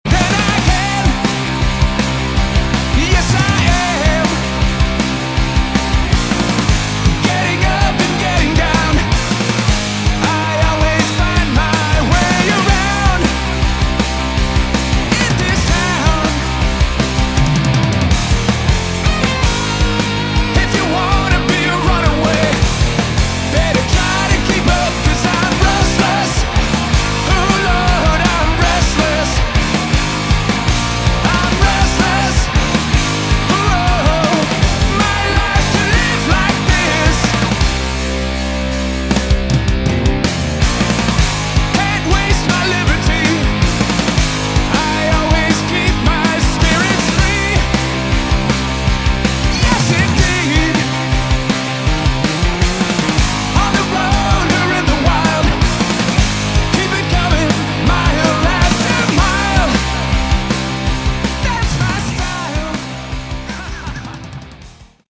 Category: Hard Rock
Vocals, All instruments